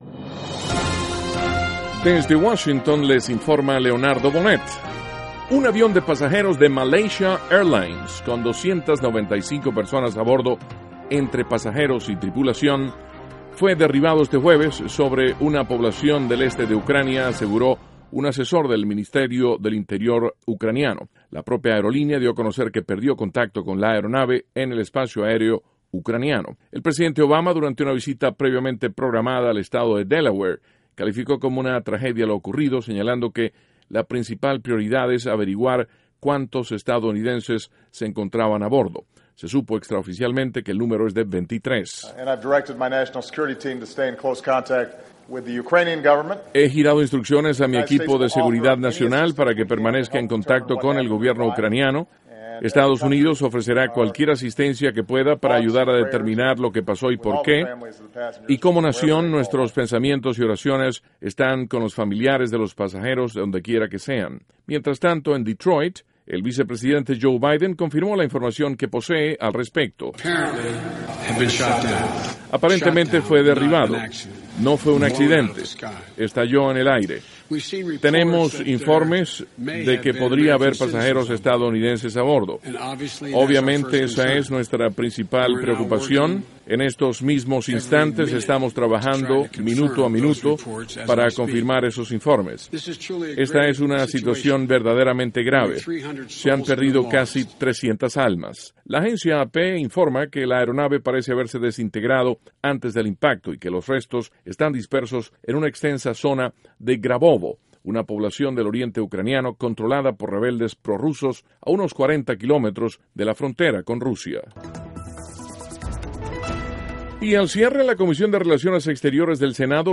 NOTICIAS - JUEVES, 17 DE JULIO, 2014
Duración: 3:21 Contenido: 1.- El presidente Obama califica de tragedia lo ocurrido con un avión de pasajeros en Ucrania. (Sonido Obama) 2.- Senador Marco Rubio afirma que los gobiernos de Honduras, Guatemala y El Salvador, peinsan que la crisis migratoria fronteriza debe ser resuelta por Estados Unidos y no por ellos. (Sonido Rubio) 3.- Subcomisión de la Cámara de Representantes realiza audiencia sobre confiscación de cuentas bancarias pertenecientes a cabecillas del delito.